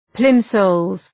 Προφορά
{‘plımzəlz}